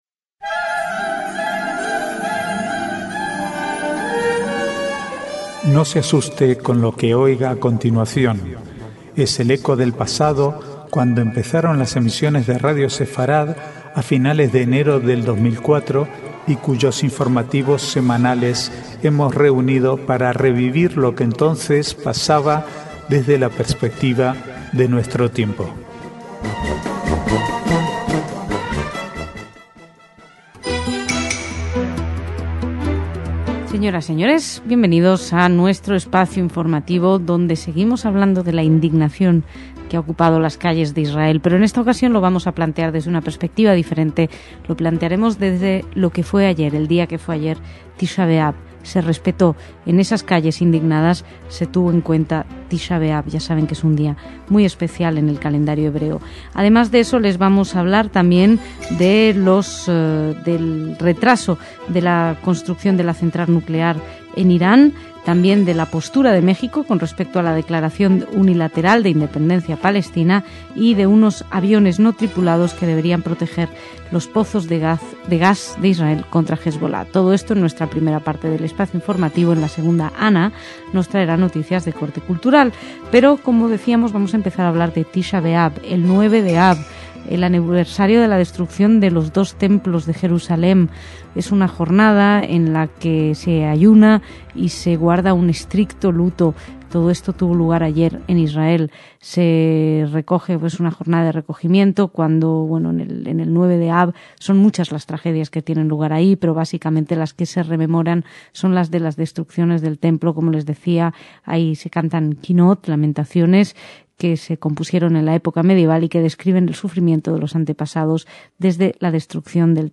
Archivo de noticias del 10 al 16/8/2011